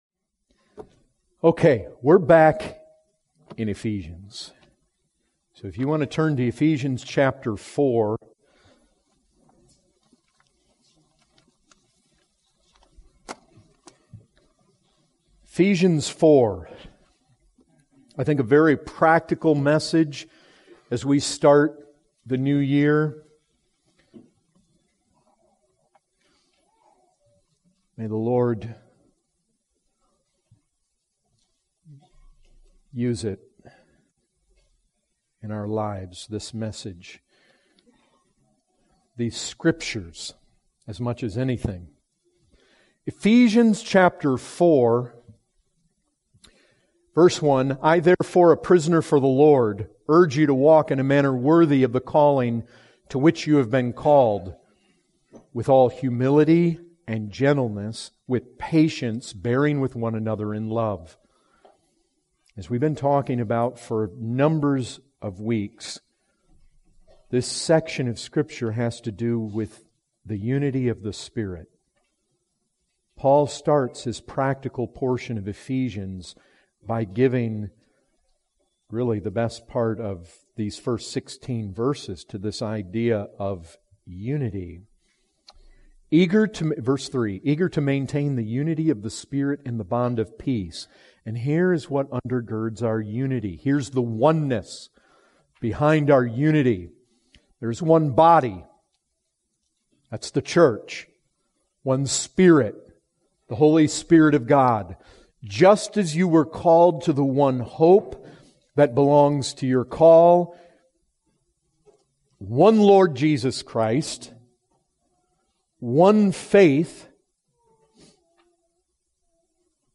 2019 Category: Full Sermons Topic